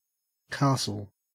castle-au.mp3